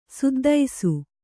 ♪ suddaisu